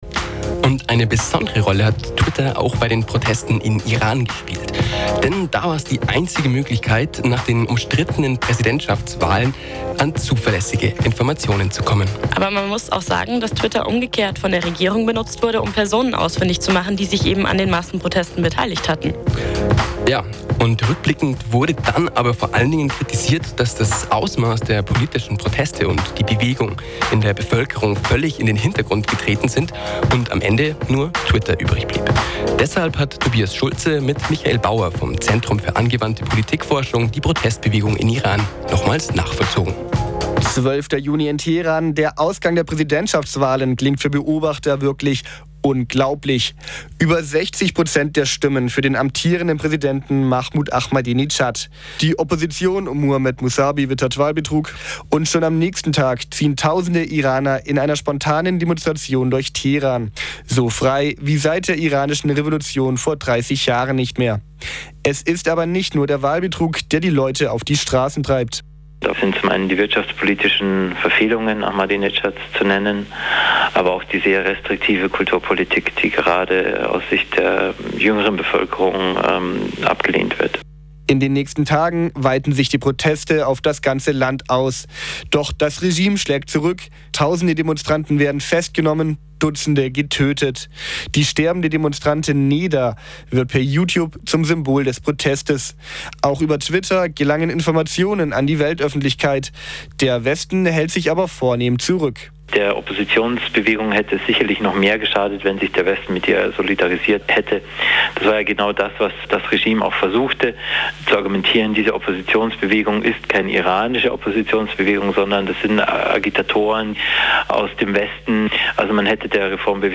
Interview
22.12.2009 · Radio M94,5